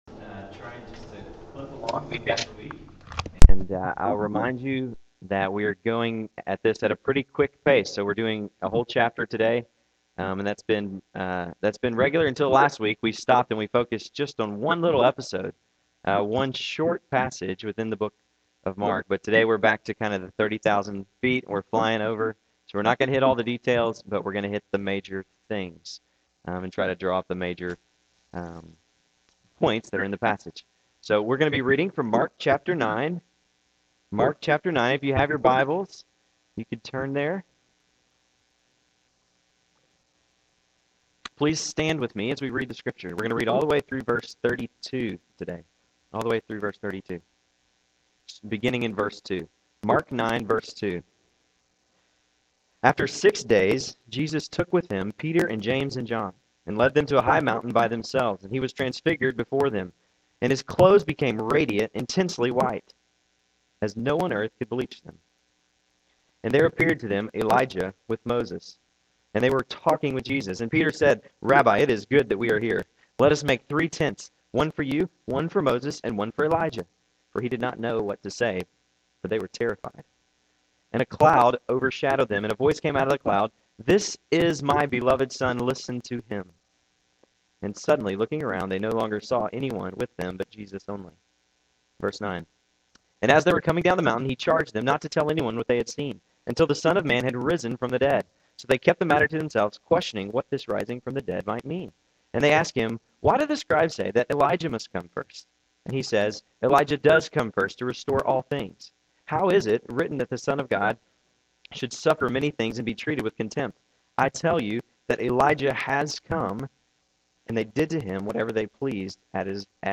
Sermon audio for 9/27 and 10/4
In case you missed it, you can now listen to the audio for sermons delivered on 09/27/2009 and 10/04/2009.